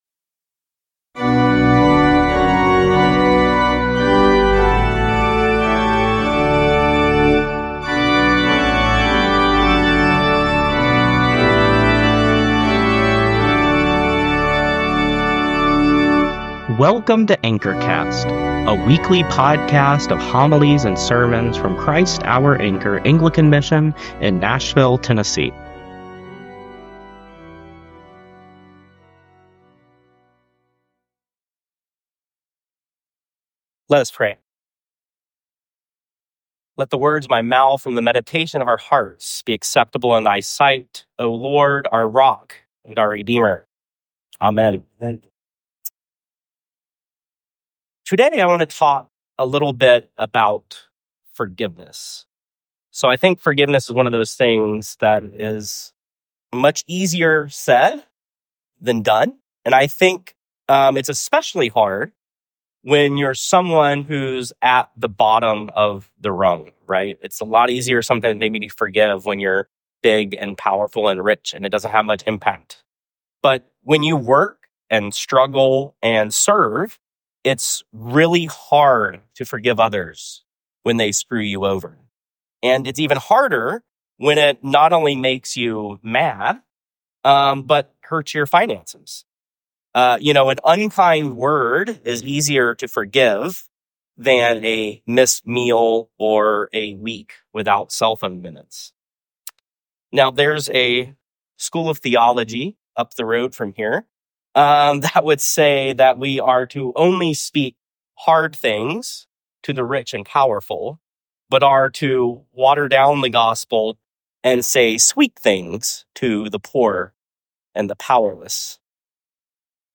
sermon-2024-10-31.mp3